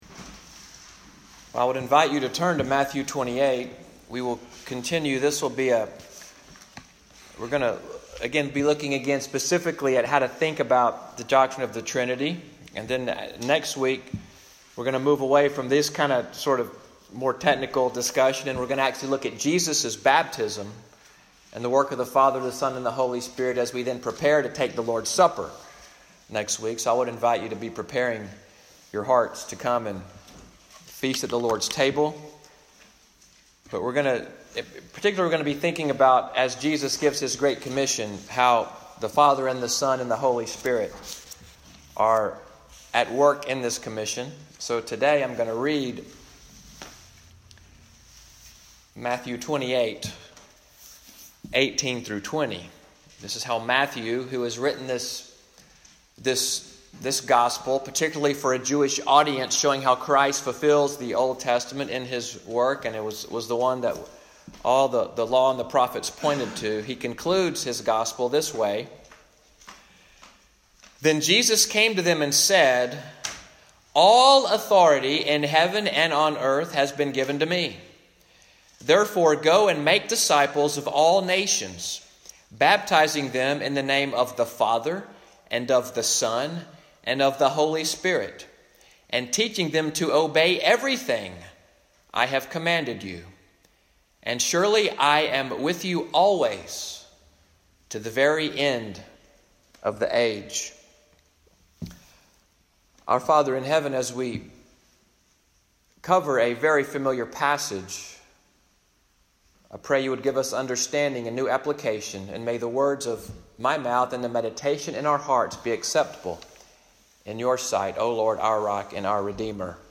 Sermon audio from Little Sandy Ridge Presbyterian Church in Fort Deposit, Alabama. Morning worship on February 24, 2019.